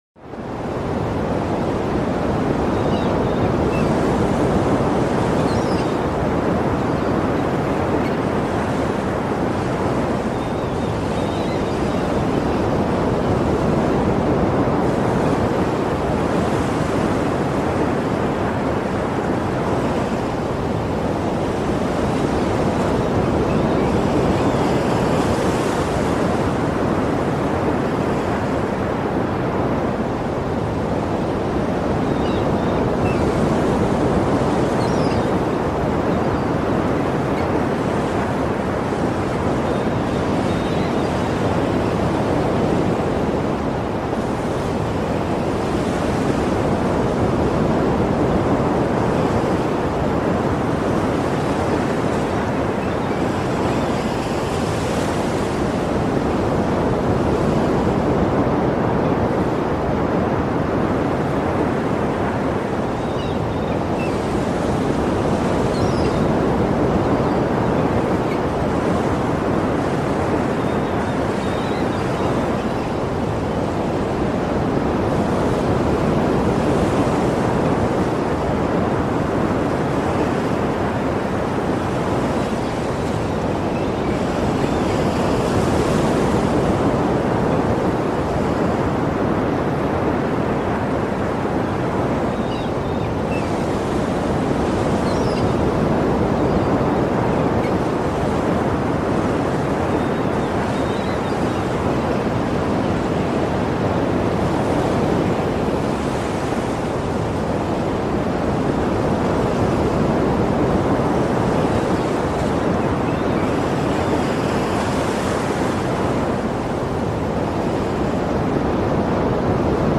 1-STUNDE-MEER-WELLEN: Entspannung mit Meeresrauschen (therapeutisch)